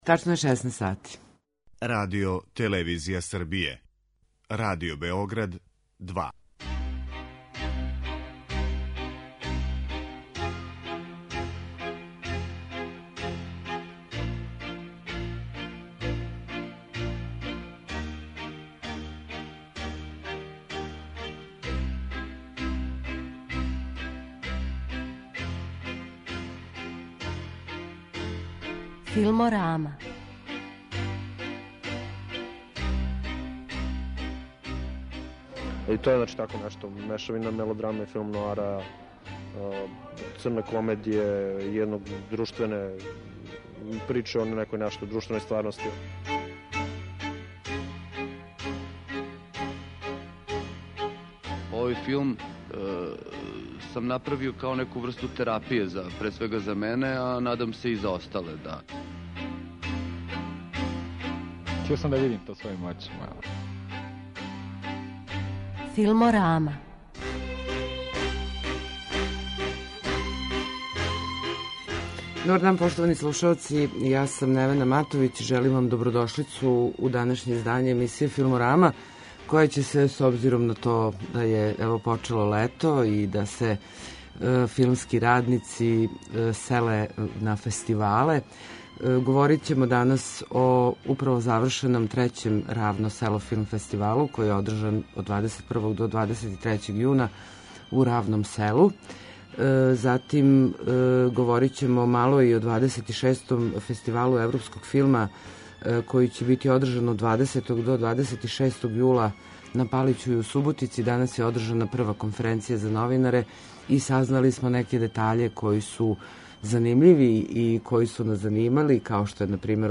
У данашњој Филморами слушаћете разговоре снимљене током трајања трећег издања Филмског фестивала „Равно Село", који је одржан крајем прошле недеље.